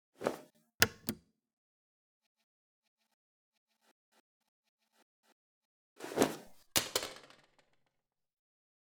Używanie i anulowanie odtwarzacza kaset
Use_and_discard_tape.mp3